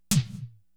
80STOM2.WAV